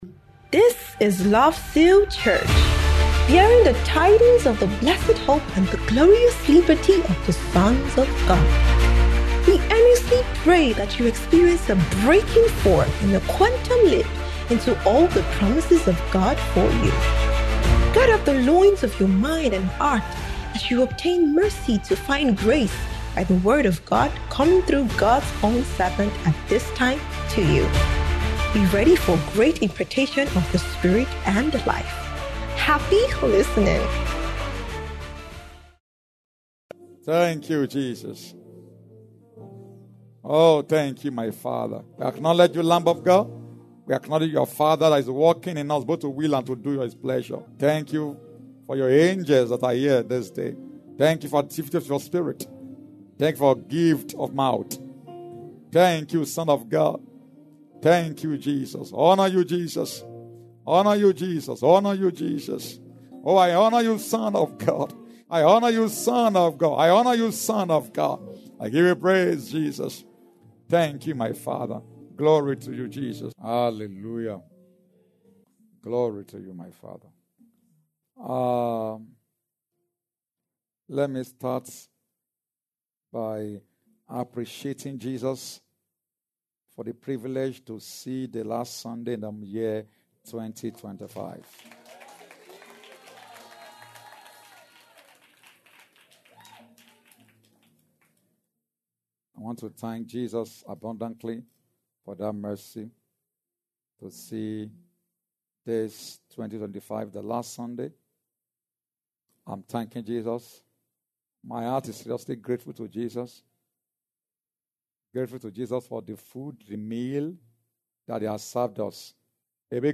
Year End Sunday Special Service